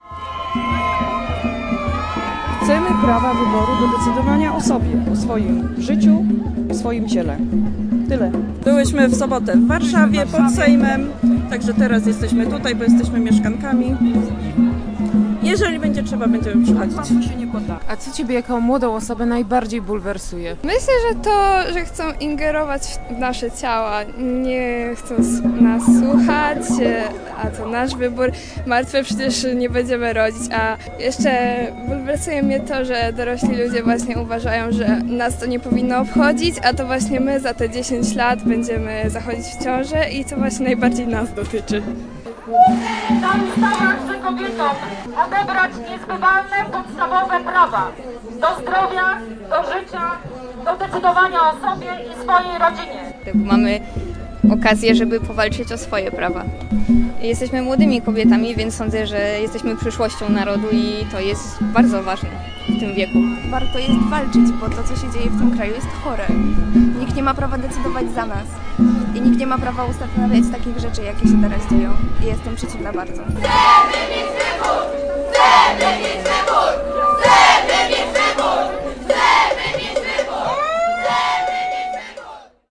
Manifestacja rozpoczęła się pod ratuszem. Co mówiły protestujące kobiety?